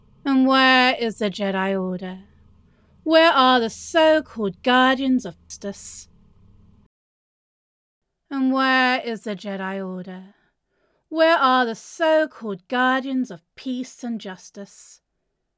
Here's an example of the audio glitches and why I have to re-edit from raw audio.